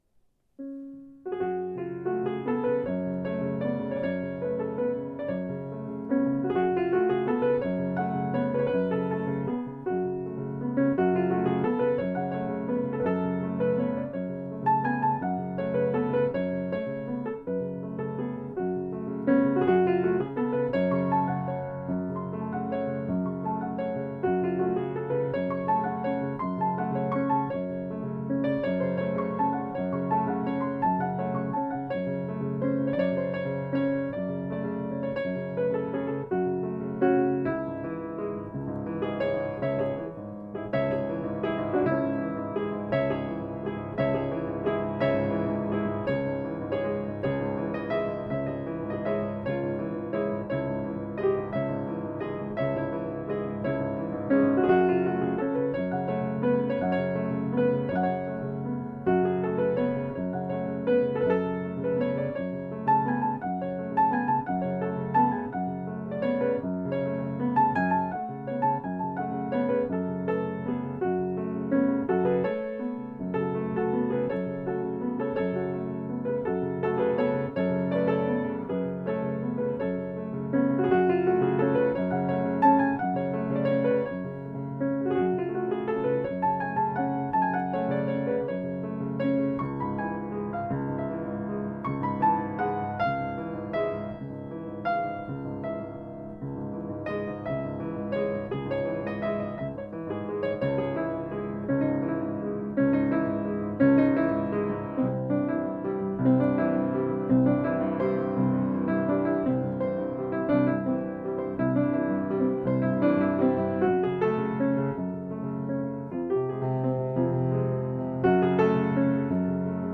improv 1-30-13 after teaching Chopin